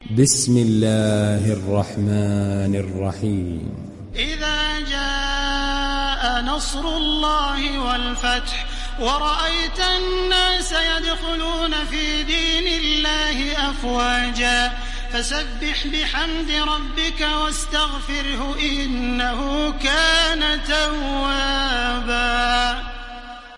Download Surah An Nasr Taraweeh Makkah 1430